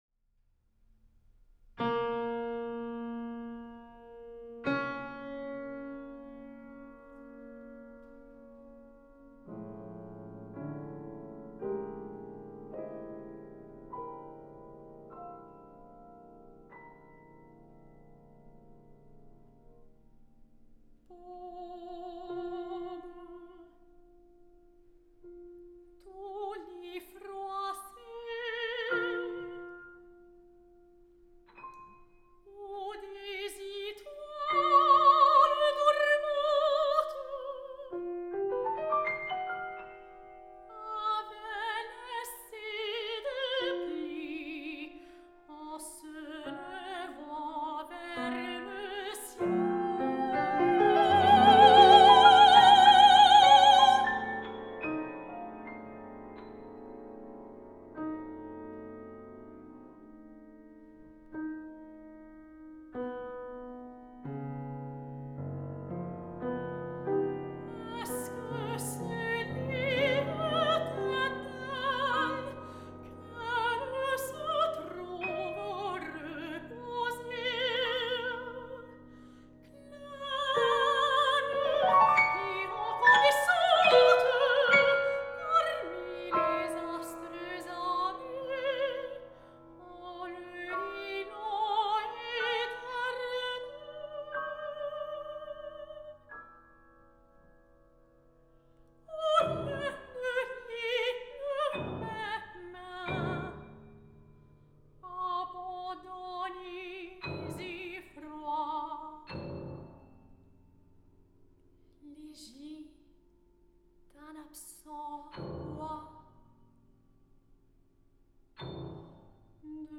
Versión voz y piano.
soprano.